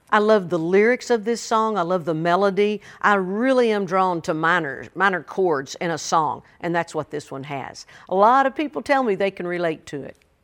Reba McEntire says she loves everything about her new song, "I Can't."